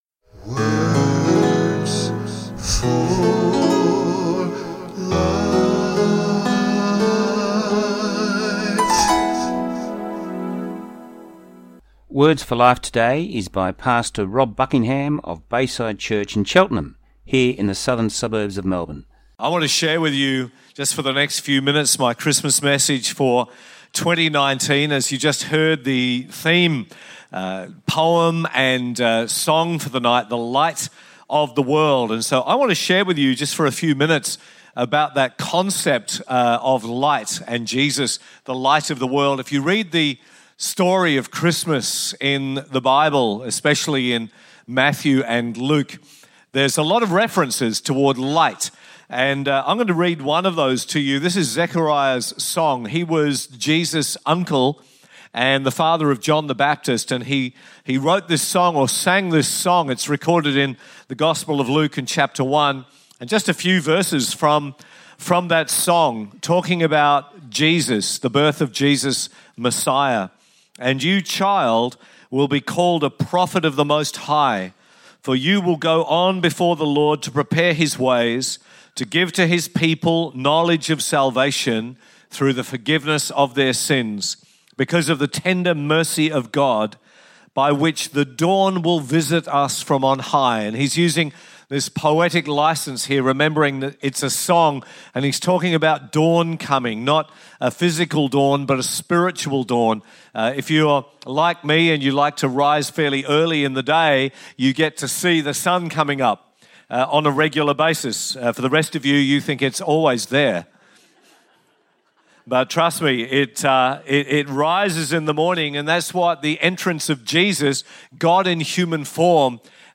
Songs of Hope Christmas sermon this morning